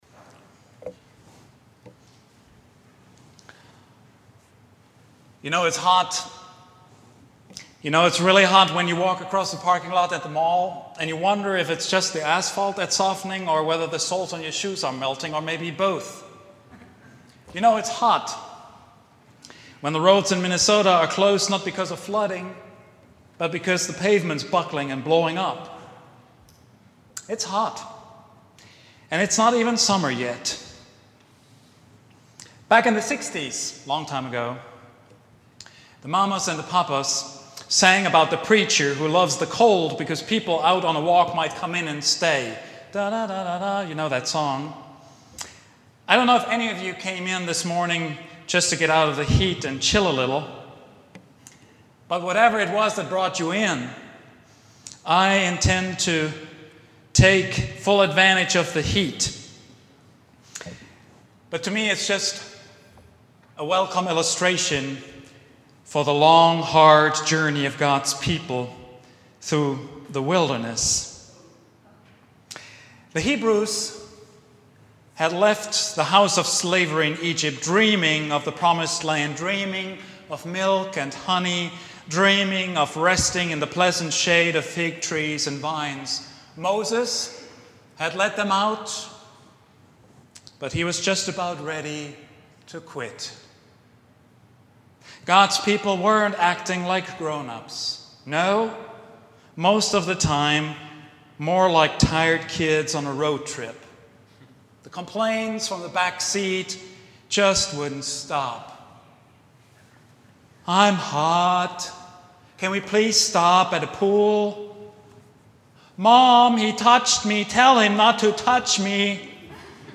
Joshua's Worries and Moses' Dreams — Vine Street Christian Church